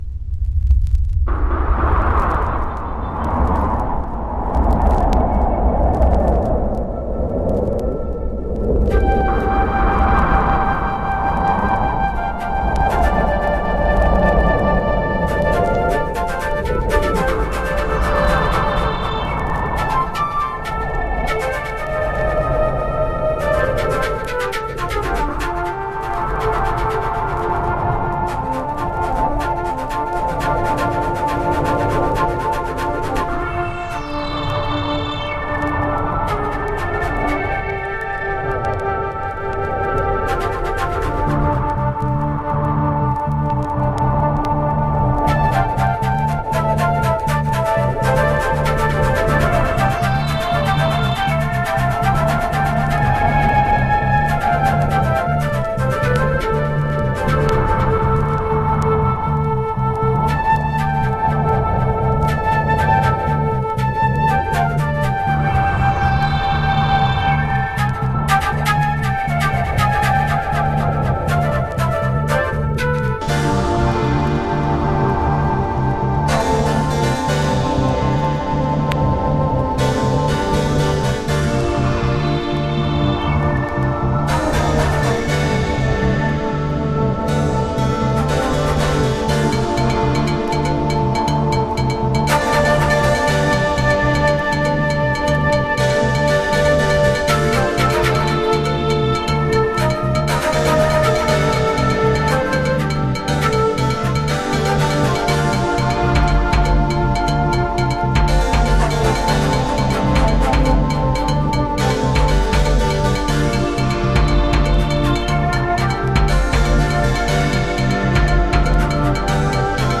Early House / 90's Techno
サックスヴァージョンとフルートヴァージョンを収録。